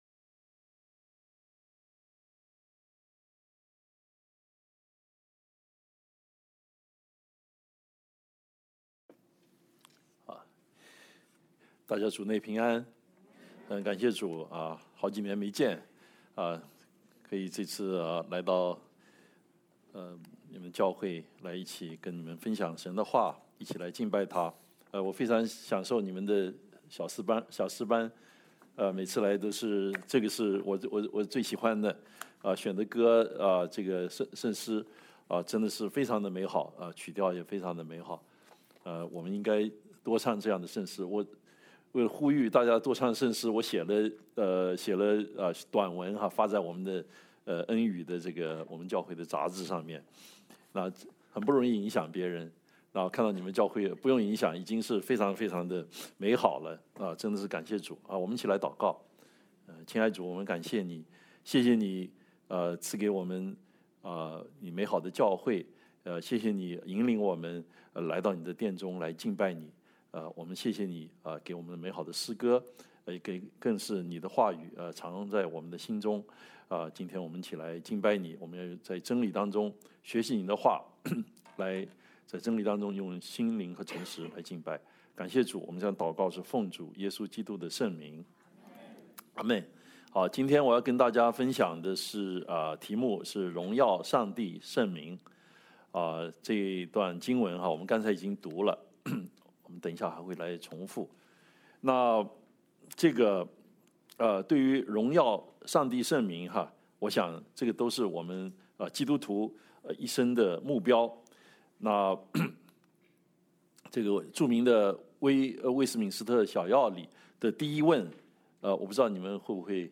John 12:28–33 Service Type: 主日证道 Download Files Notes « 危機下的平安 因信称义之福 » Submit a Comment Cancel reply Your email address will not be published.